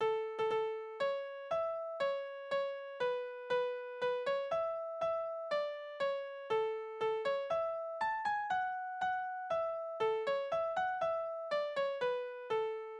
Balladen: Die unglückliche Schützin
Tonart: A-Dur
Taktart: 4/4
Tonumfang: Oktave
Besetzung: vokal